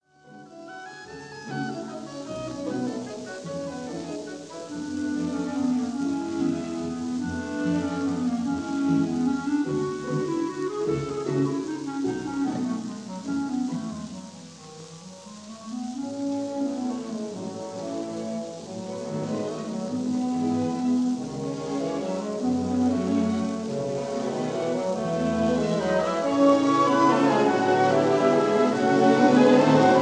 full frequency range recording